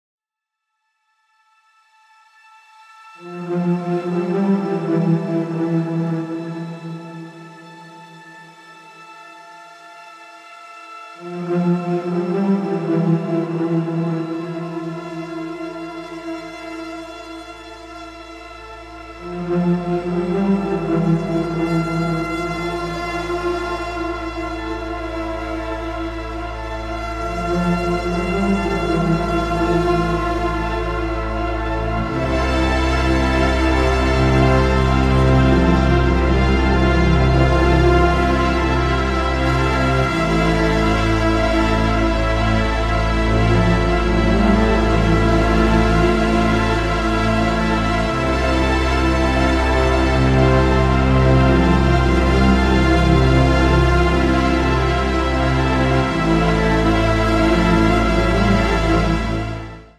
Evolving, atmospheric pads and soundscapes
Audio demos
A cinematic palette of multi-layered textures